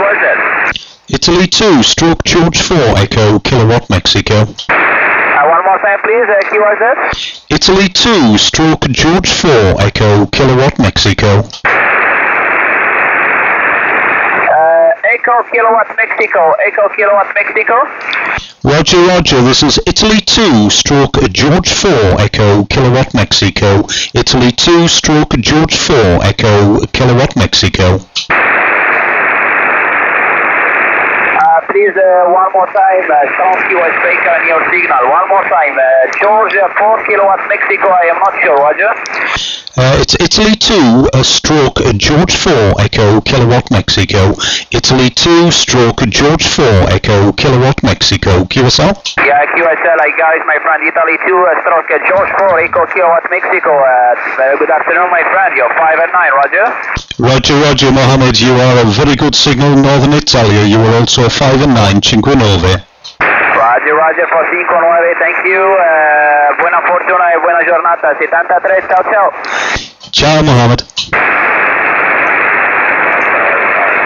Band conditions this week were very poor but managed to get a few on 40-10m